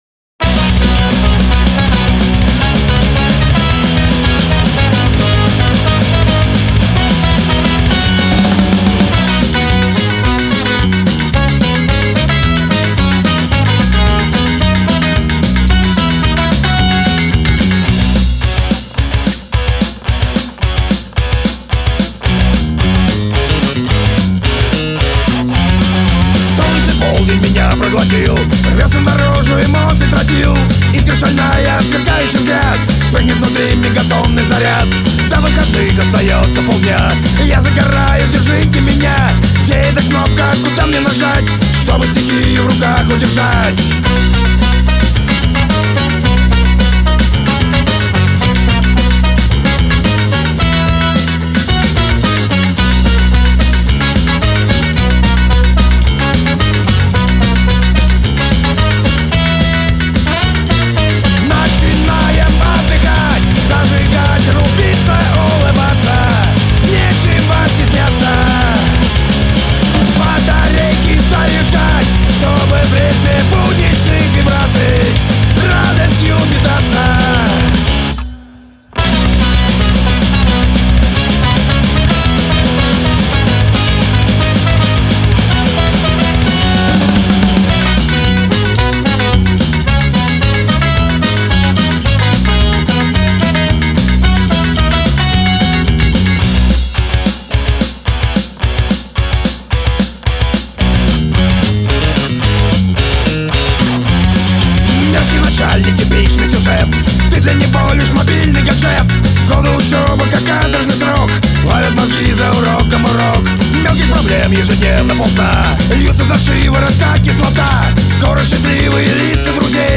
• Genre: Ska-Punk